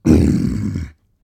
roar_human.ogg